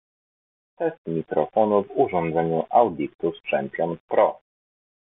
Z drugiej jednak, porównajcie sami jakość nagrania:
Mikrofon dostępny w wariancie bezprzewodowym…
Dodam, że kilka prób nie nadawało się do porównania, gdyż materiał nagrany poprzez Bluetooth mocno przerywał i za bardzo zniekształcał głos.